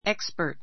ékspəː r t エ クス パ～ ト （ ⦣ アクセントの位置に注意）